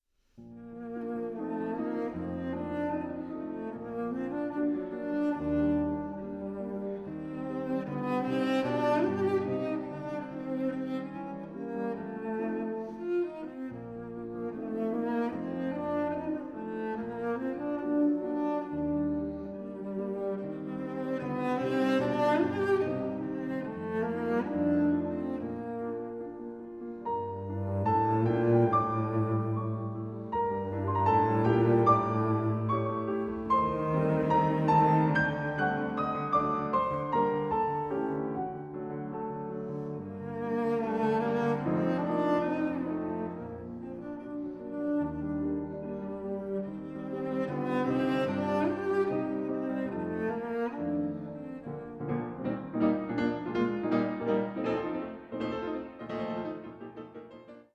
Sonata for piano and violoncello in B flat, Op 45 (1838)